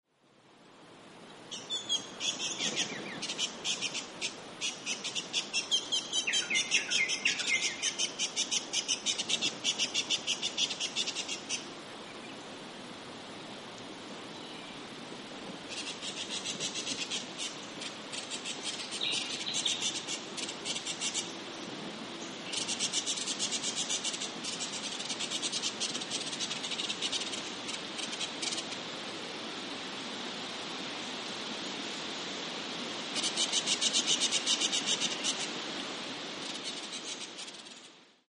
White-browed Scrubwren - Sericornis frontalis
Voice: buzzy scolding; repeated 'ts-cheer'.
Call 1: scolding
Whitebr_Scrubwren_scold2.mp3